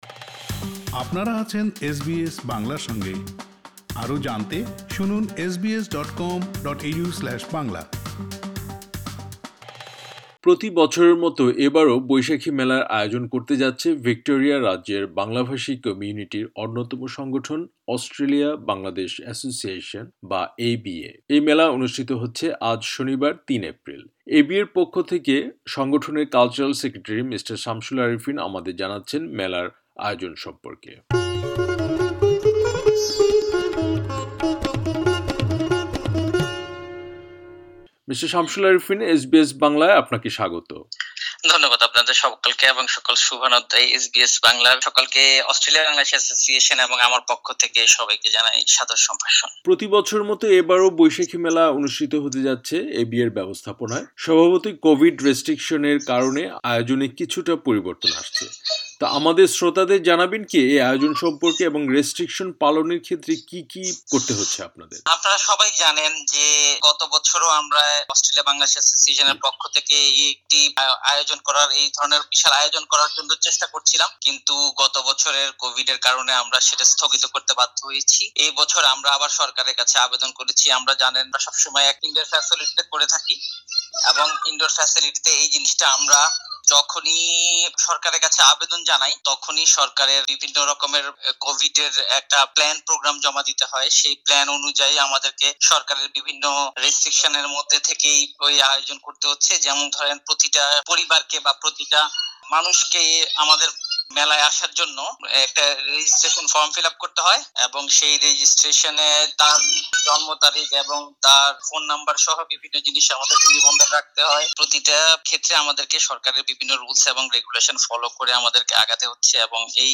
ABA পুরো সাক্ষাৎকারটি শুনতে ওপরের অডিও প্লেয়ারে ক্লিক করুন।